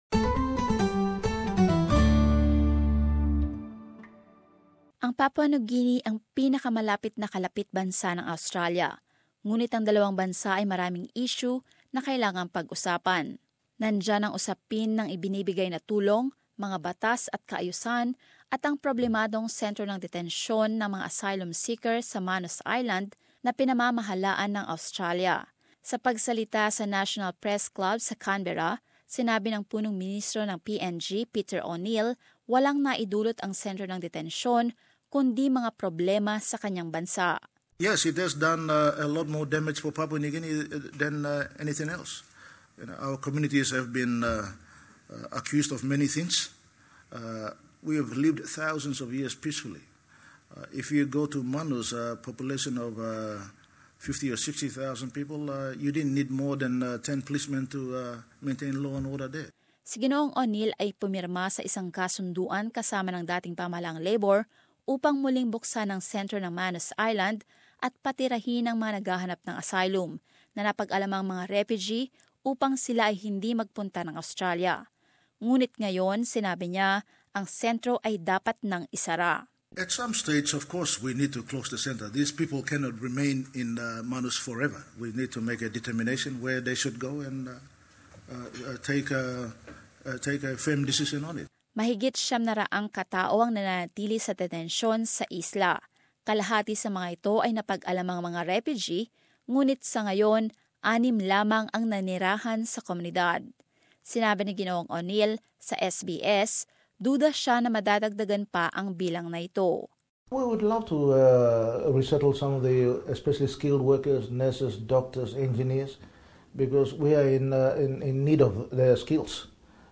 In an interview with SBS World News, Peter O'Neill has suggested very few refugees will ever be resettled in Papua New Guinea, despite the agreement with Australia.